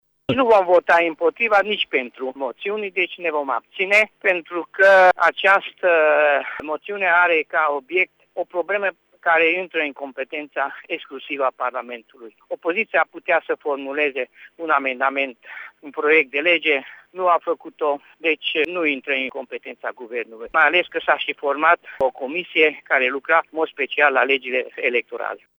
La rândul lor, parlamentarii UDMR au decis ca mâine să se abțină de la vot, a precizat, pentru RTM, deputatul Kerekes Karoly: